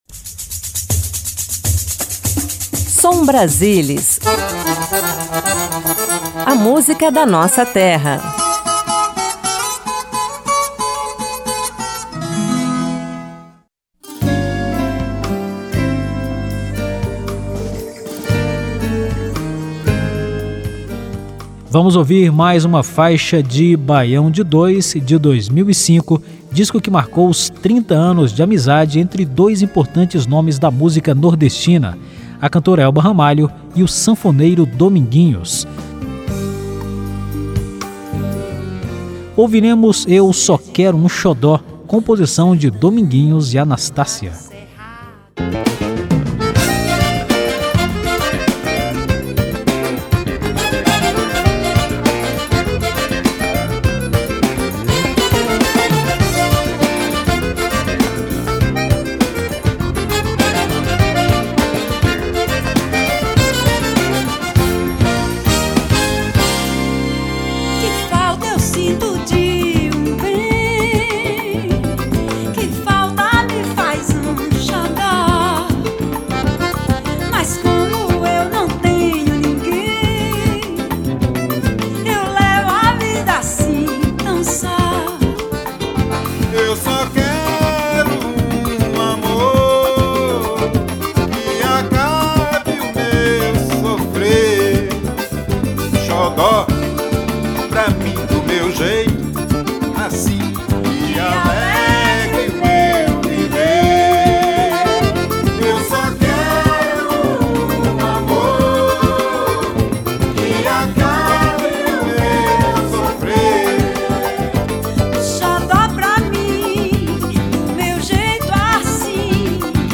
Música regional Ritmos nordestinos Forró Baião Xote